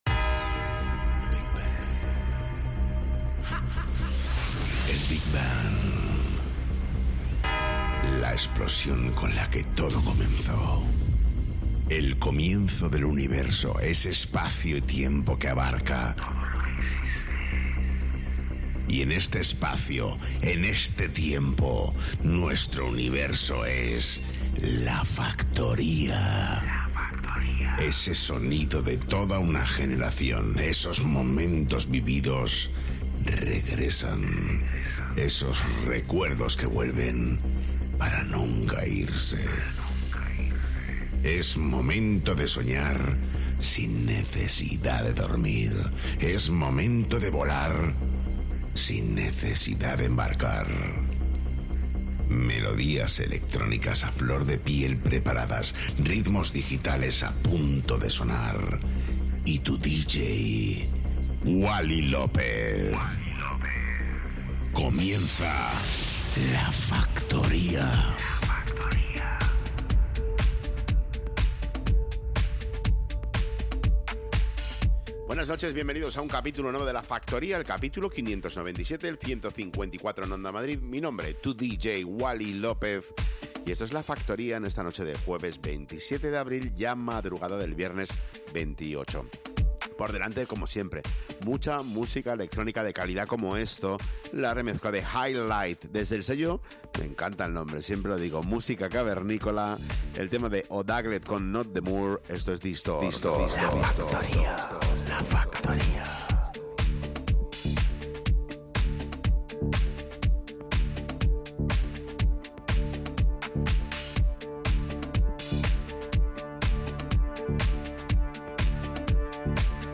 el DJ más internacional de Madrid